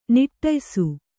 ♪ niṭṭaysu